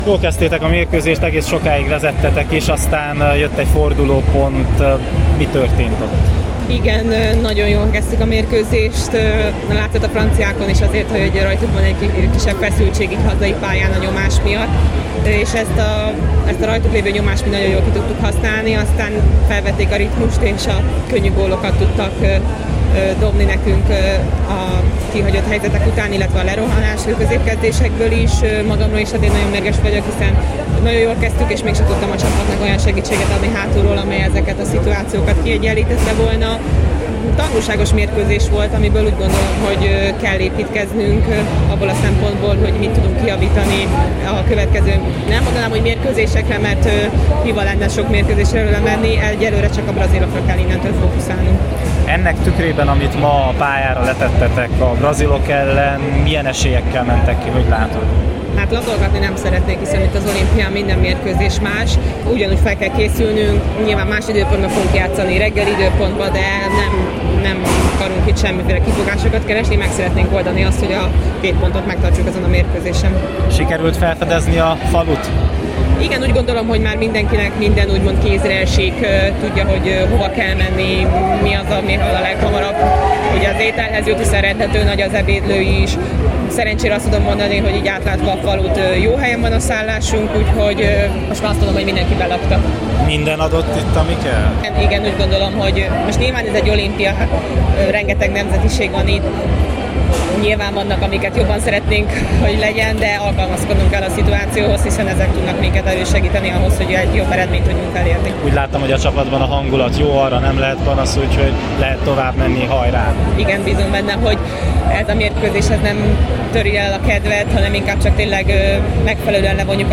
A találkozó után a főszereplőket kérdeztük.
Böde-Bíró Blanka kapus: